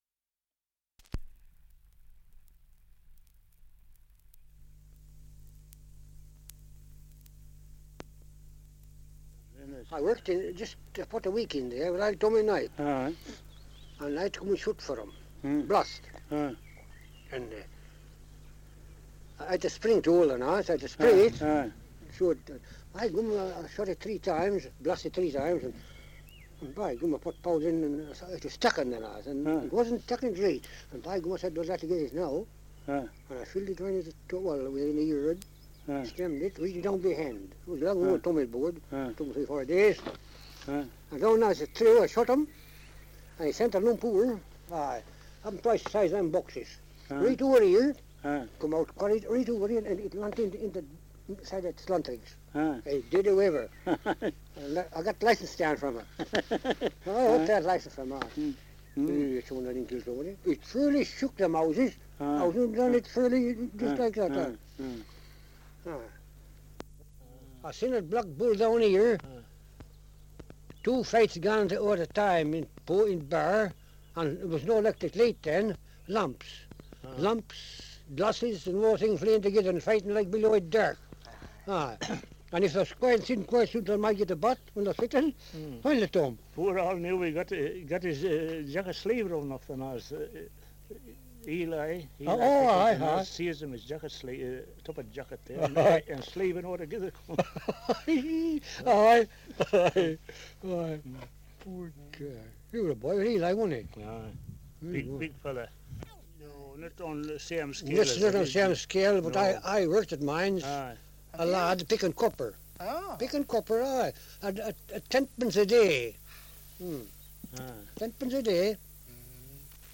2 - Survey of English Dialects recording in Coniston, Lancashire
78 r.p.m., cellulose nitrate on aluminium